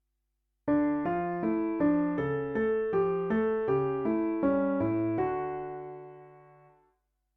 Question 4: D
Major key was: Question 4